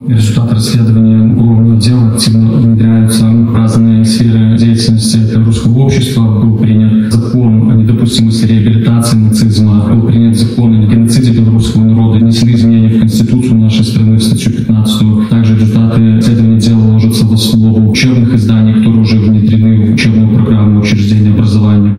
В Барановичах состоялась премьера фильма»Дневник доктора Левинбука»
О том, какие исследования проводятся Генеральной прокуратурой республики по фактам совершения нацистскими преступниками в годы войны геноцида белорусского народа, рассказал присутствующим заместитель Барановичского межрайонного прокурора Станислав Давидавичус.
zam.-prokurora.ogg